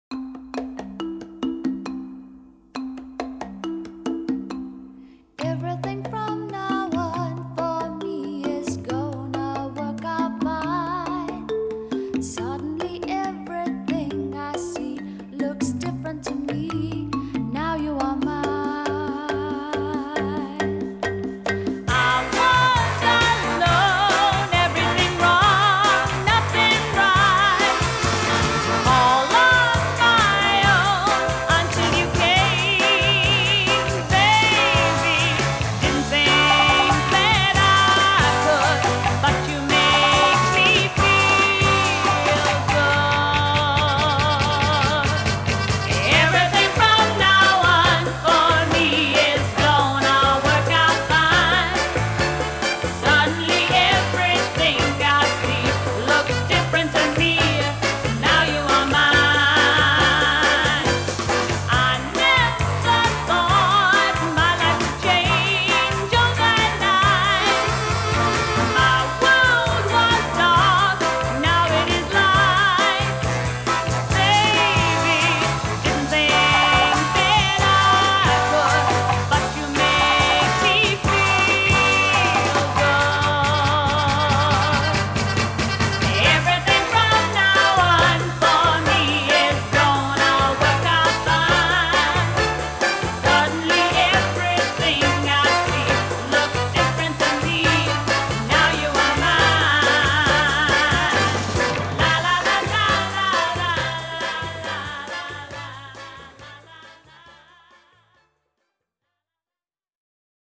Vinyl, 7", 45 RPM, Single
Genre: Pop
Style: Vocal